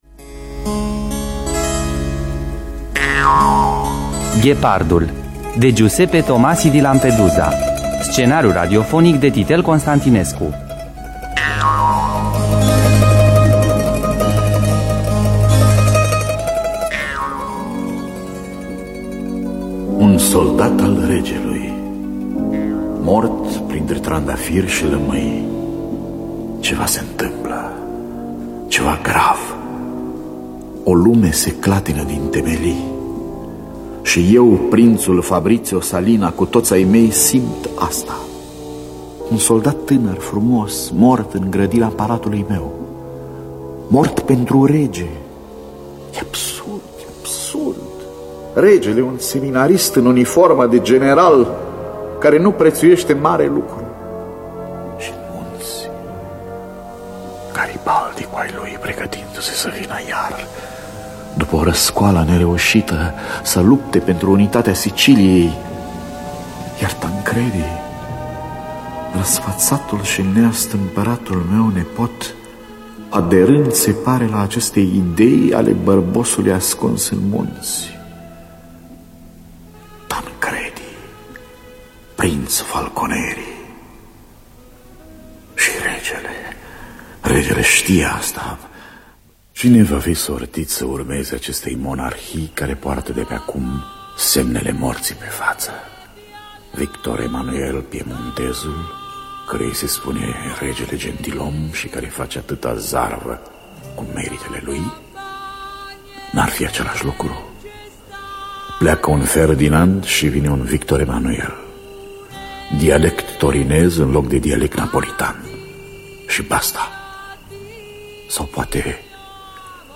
Biografii, memorii: "Ghepardul" de Giuseppe Tomasi di Lampedusa.